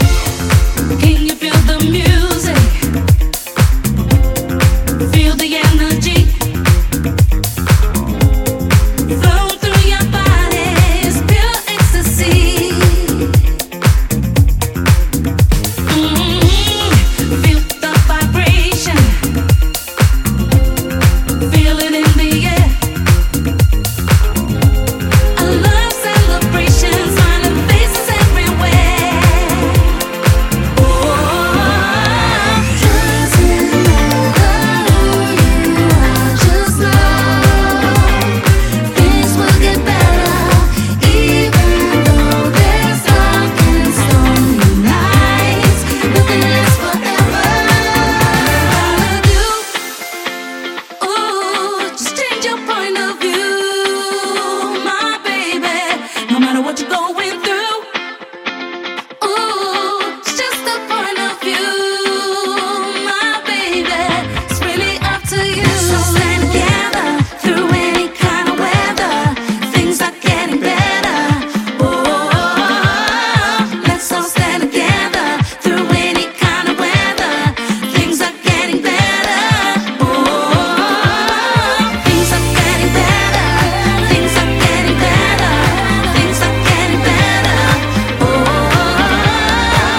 ディスコにインスパイアされた爽快ブギー・ディスコ・ハウス！
ジャンル(スタイル) DISCO / HOUSE